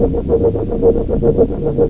tauChargeLoop2.ogg